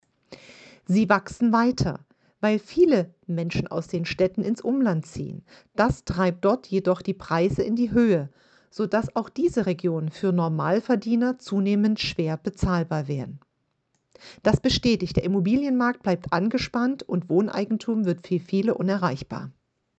Sie ist Immobilienexpertin und Maklerin.